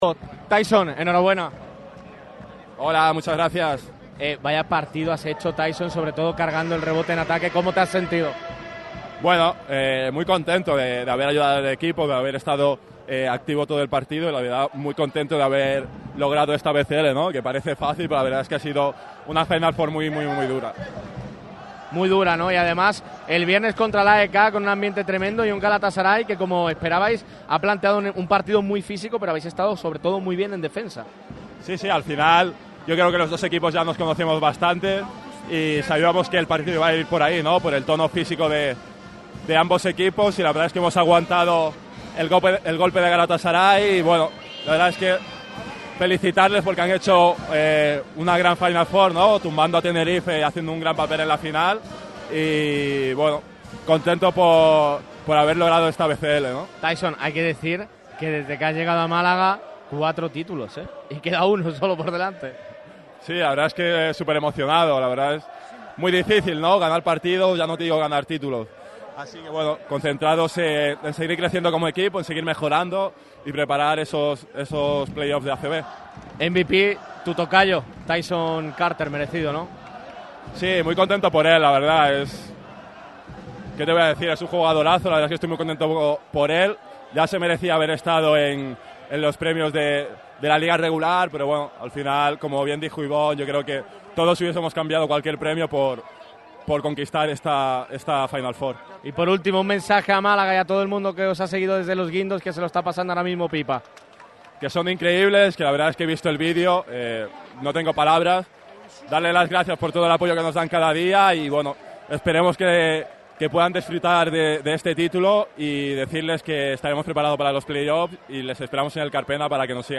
Las reacciones de los campeones de la BCL sobre el parqué del Sunel Arena.